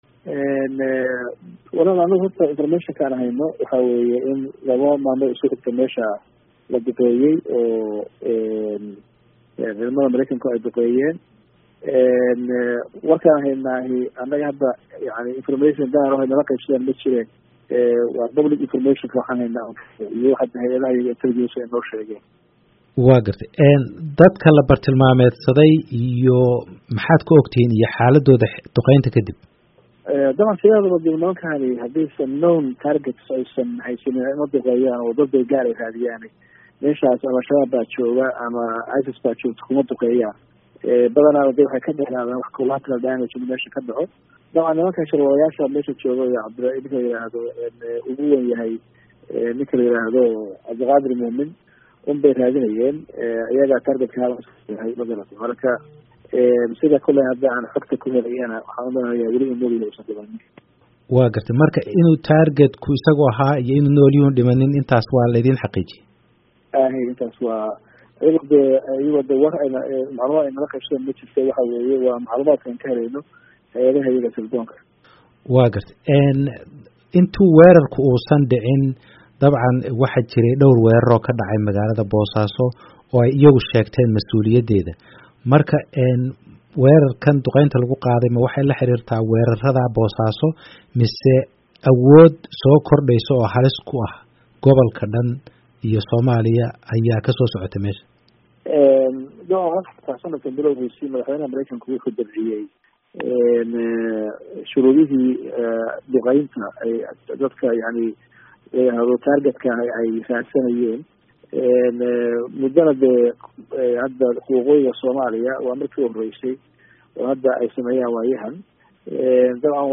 Dhegayso : Waraysi Gaar ah Madaxweynaha Puntland